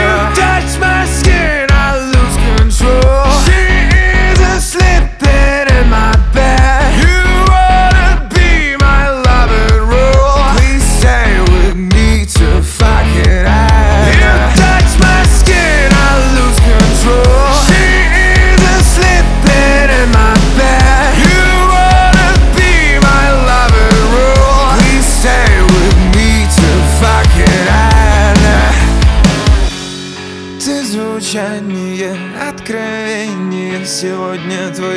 Pop Rock
Жанр: Поп музыка / Рок